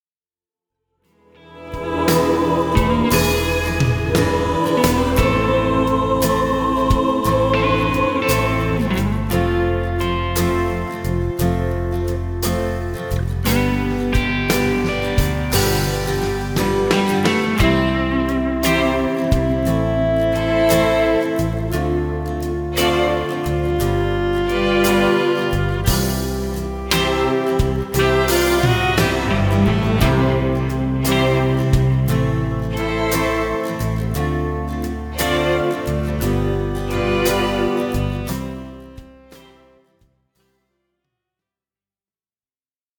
Play-Back